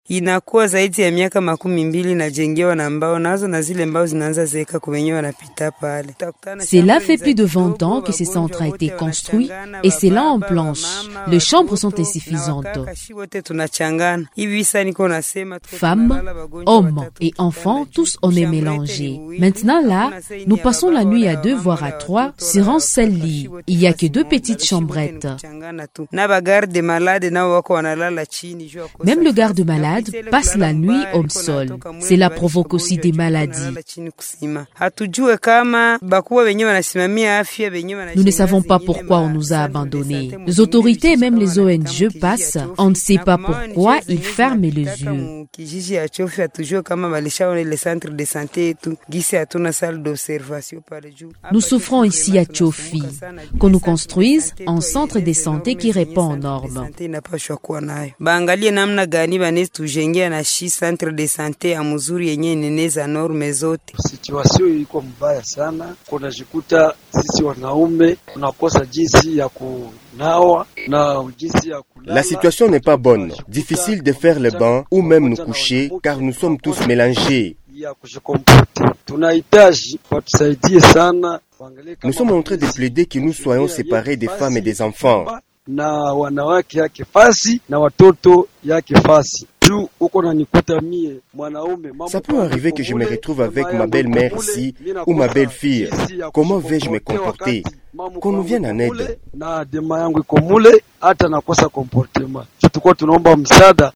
On écoute tour à tour cette femme et cet homme qui veulent voir une intervention urgente des humanitaires, de l’Etat et autres personnes de bonne volonté.